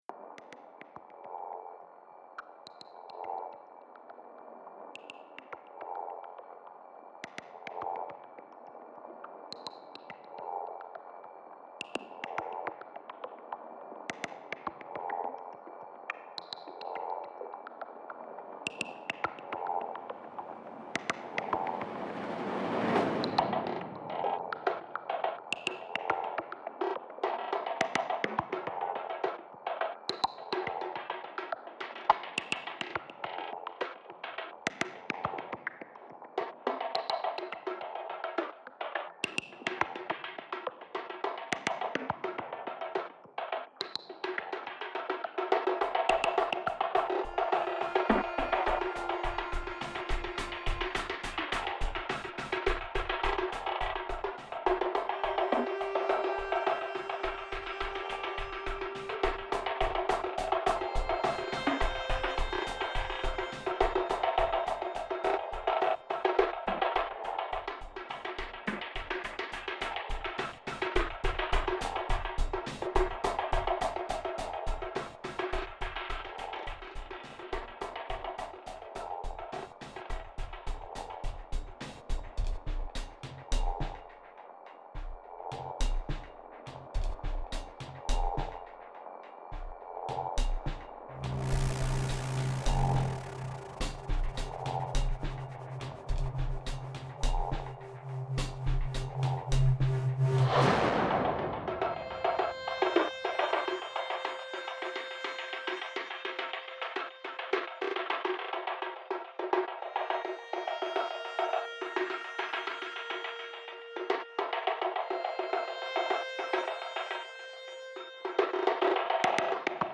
cool
geheimnisvoll
repetitiv
Electro
Drums
dissonant
nervös
elektronisch